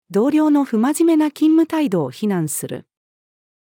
同僚の不真面目な勤務態度を非難する。-female.mp3